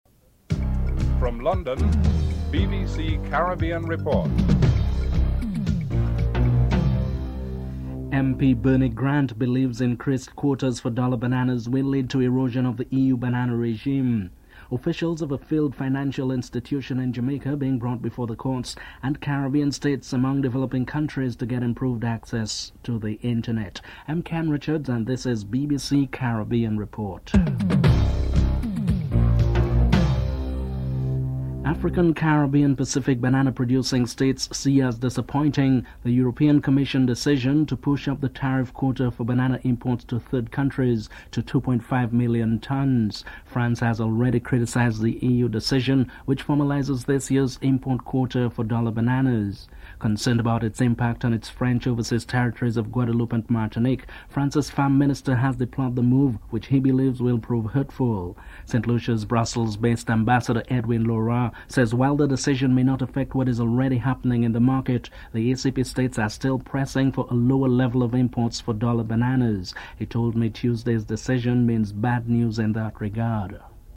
1. Headlines (00:00-00:31)